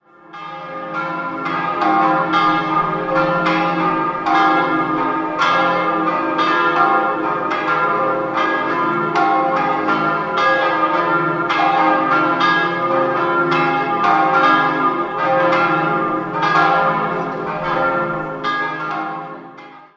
4-stimmiges Geläute: des'(-)-es'-f'-as'